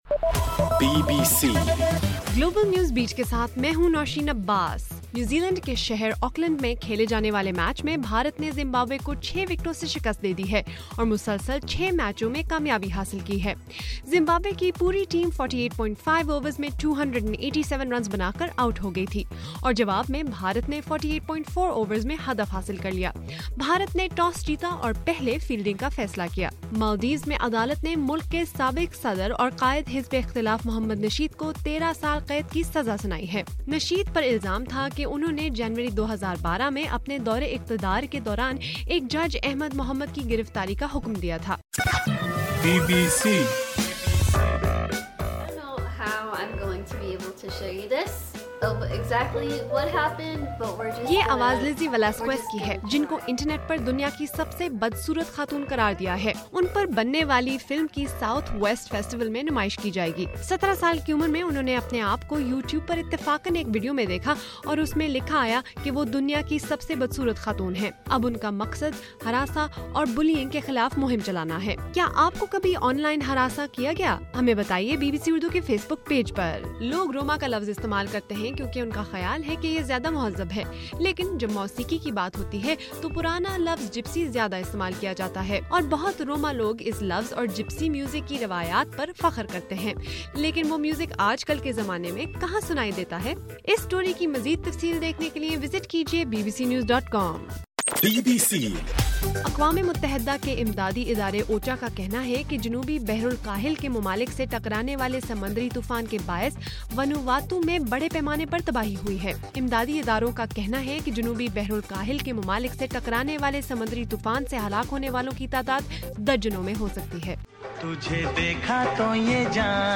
مارچ 14: رات 9 بجے کا گلوبل نیوز بیٹ بُلیٹن